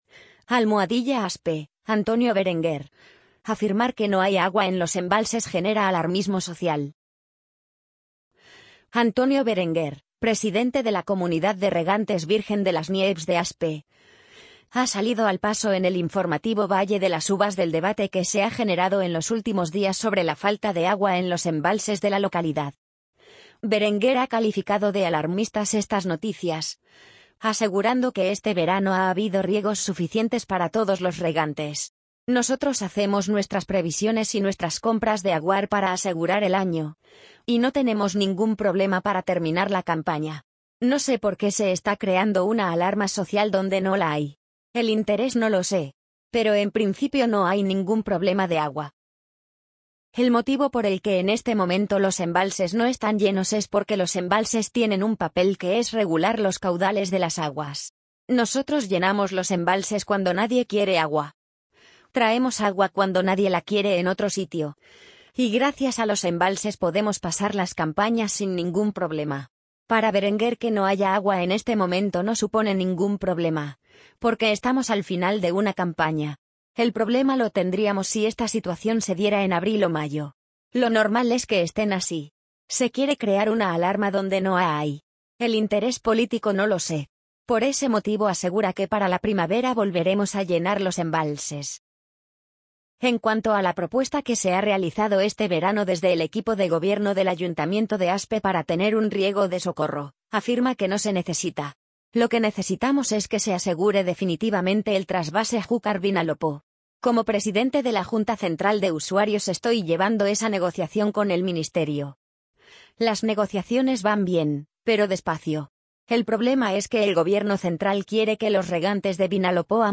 amazon_polly_45007.mp3